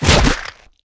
hive_wound2.wav